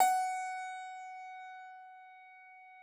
53l-pno16-F3.wav